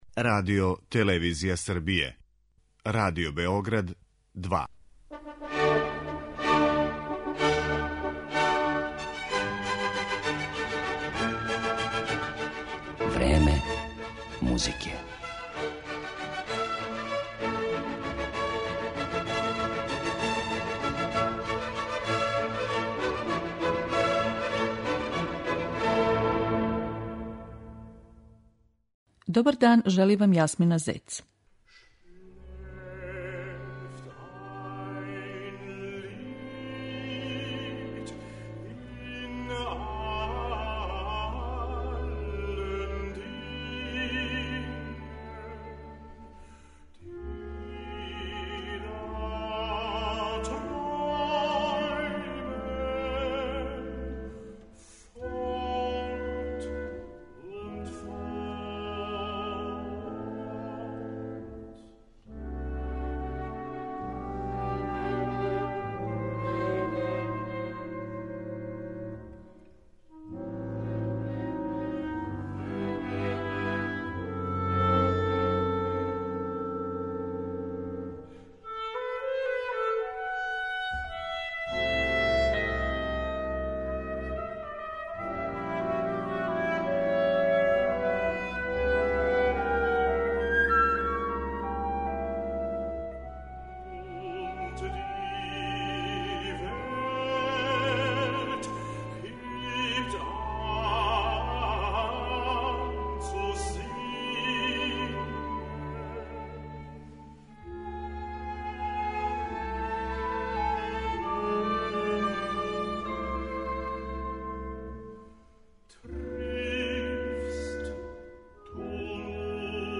Емитоваћемо део програма коју су извели познати тенор из Немачке Кристоф Прегардиjeн и ансамбл 'Контраст' на концерту у Пољској, пре две године.
Била је то прилика да још једном оправдају репутацију специјалиста за немачки лид.